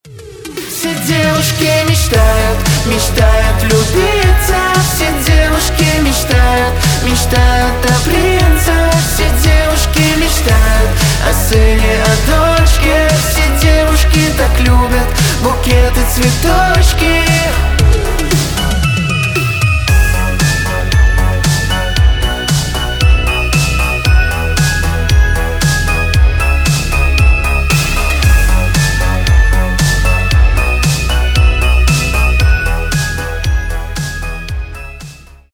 танцевальные
поп